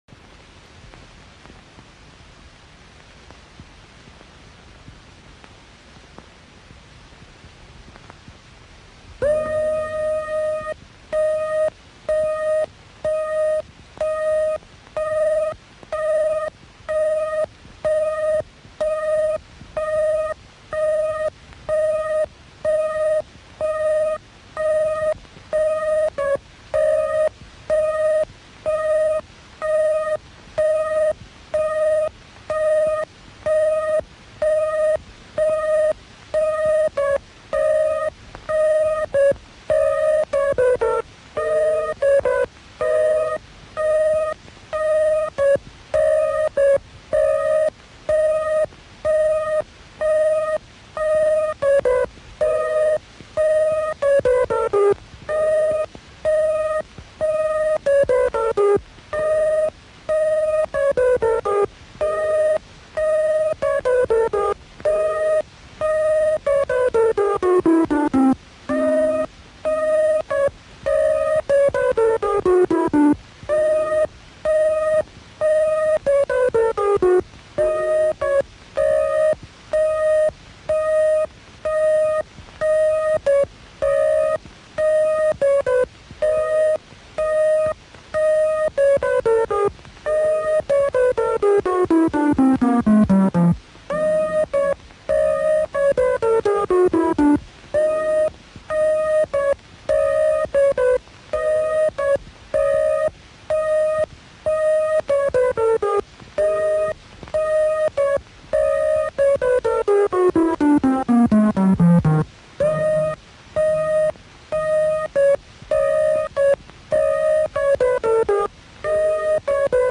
In 1974, one of the films was redone using the newer FR80 and with a synchronised soundtrack for a presentation at IFIP74 in Stockholm.
The soundtrack itself was output to a sprocketed audio tape.